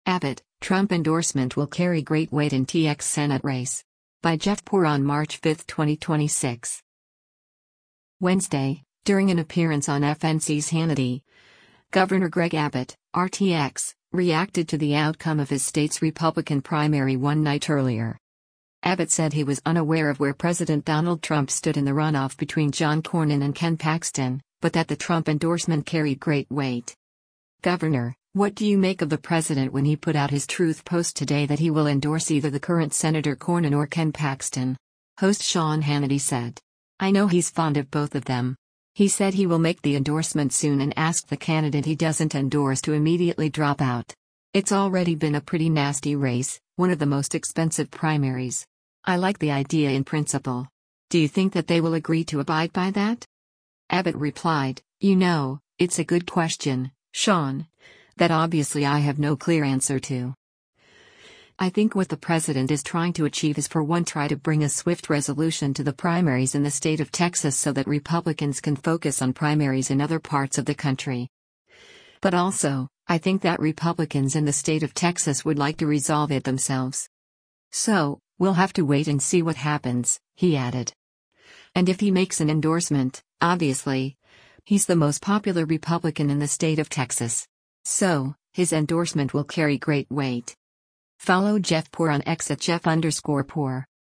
Wednesday, during an appearance on FNC’s “Hannity,” Gov. Greg Abbott (R-TX) reacted to the outcome of his state’s Republican primary one night earlier.